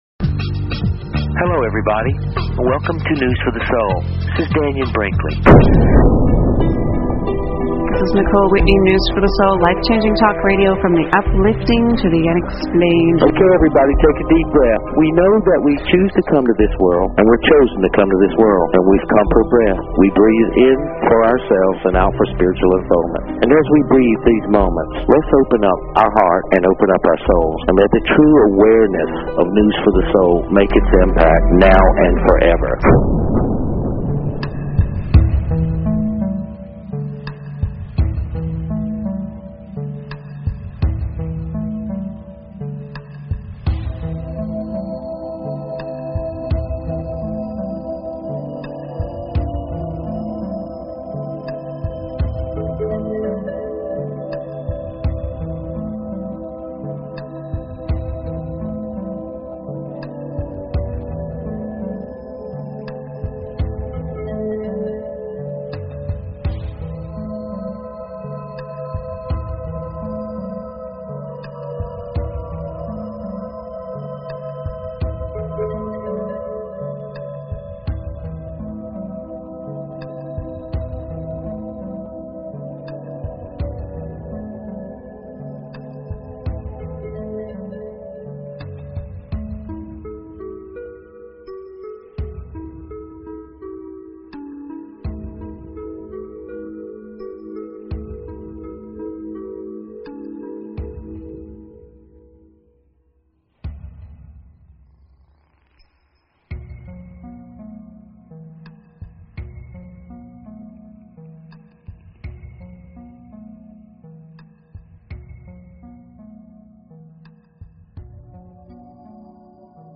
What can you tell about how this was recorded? We are opening the lines for you to call in for your free angel reading on Monday night!